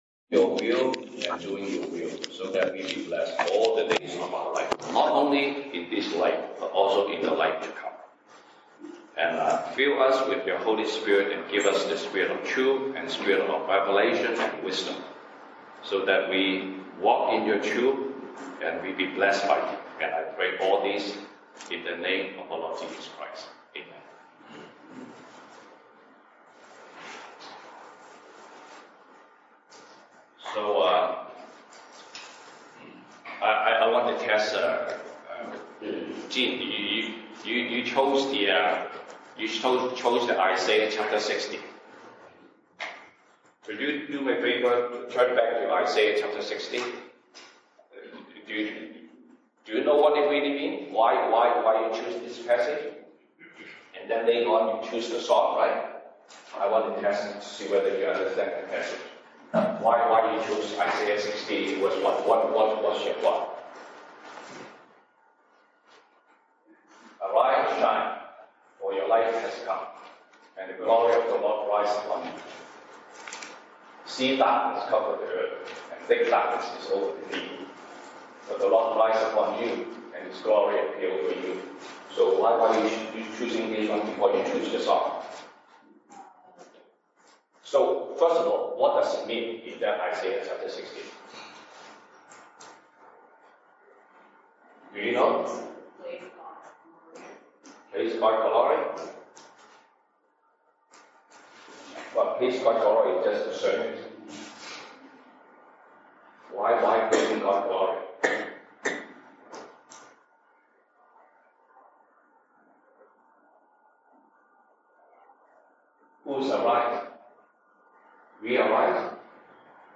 西堂證道 (英語) Sunday Service English: God’s mystery vs God’s secret wisdom